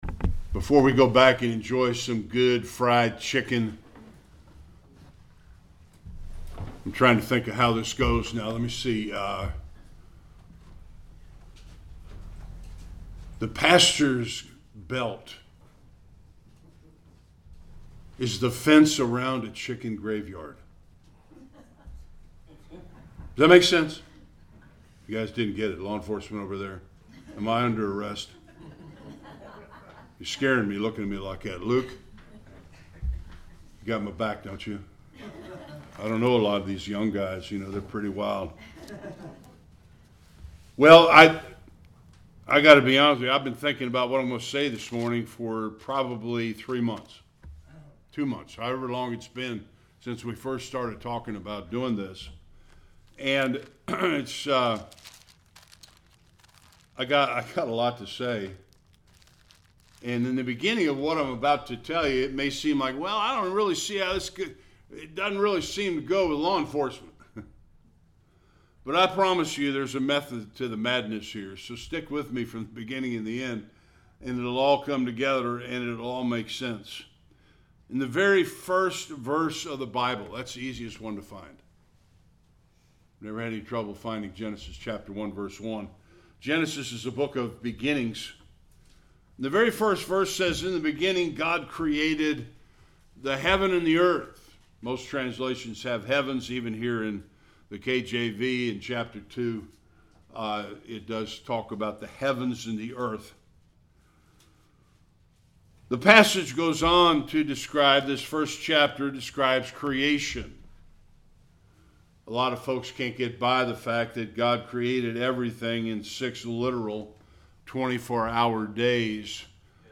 Various Passages Service Type: Sunday Worship The Christian and the government.